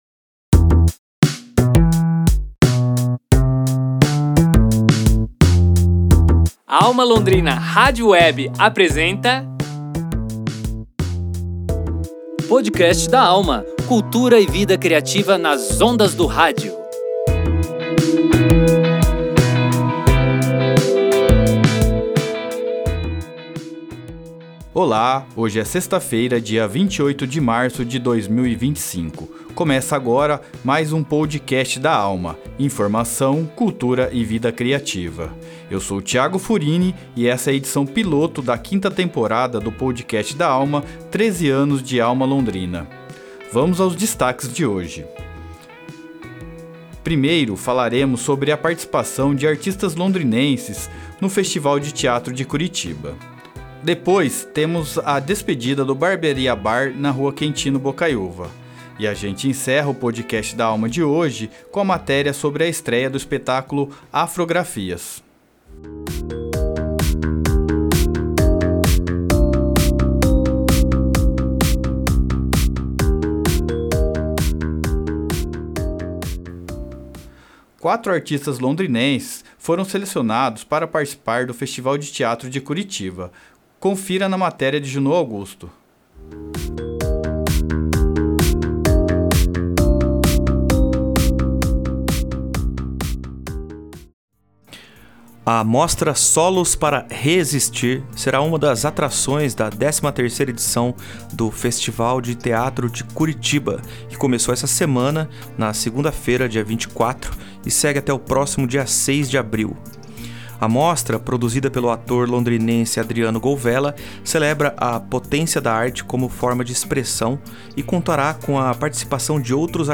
Os destaques foram a participação de artistas londrinenses no Festival de Teatro de Curitiba, a despedida do Barbearia Bar, da rua Quintino Bocaíuva e uma entrevista sobre a estreia do espetáculo Afrografias, na DAP.